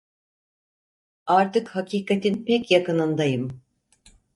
Pronounced as (IPA) /pec/